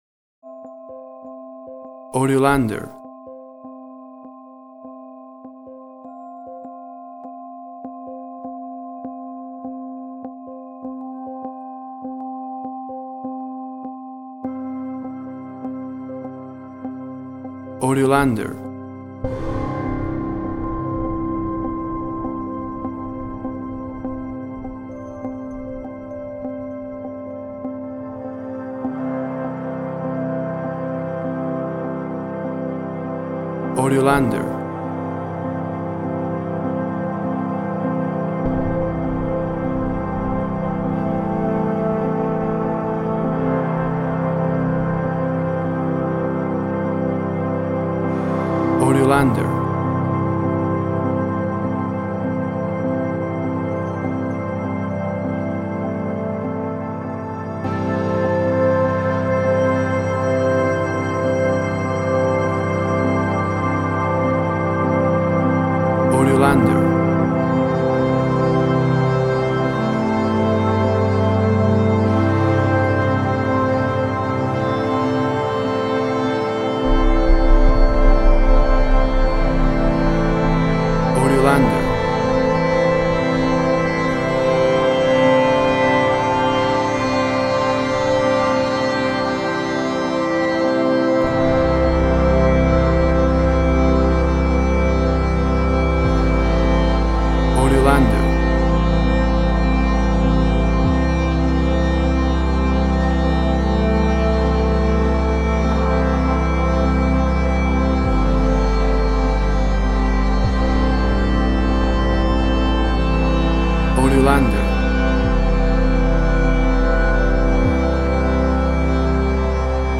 Tempo (BPM) 50